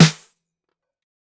DB SWUNG_SNARE AND HH.wav